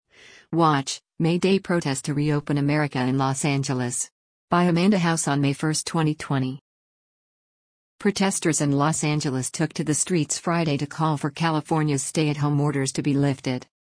Protesters in Los Angeles took to the streets Friday to call for California’s stay-at-home orders to be lifted.